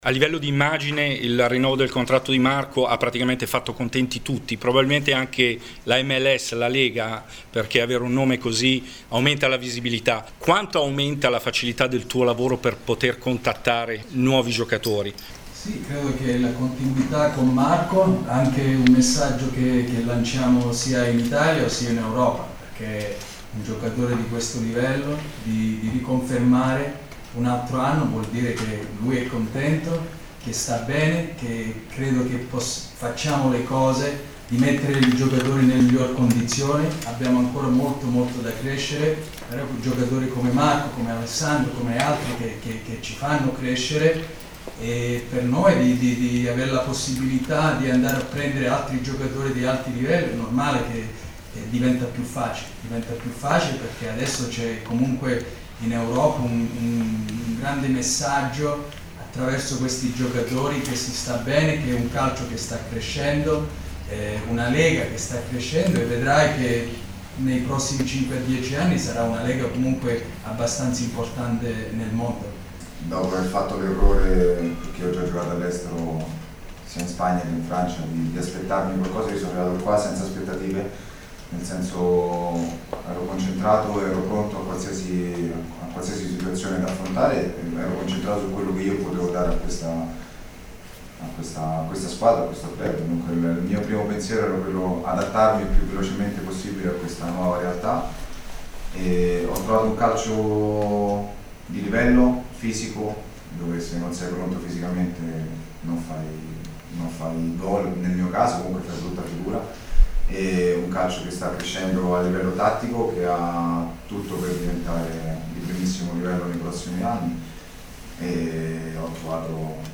Conferenza stampa: il rinnovo del contratto di Marco Di Vaio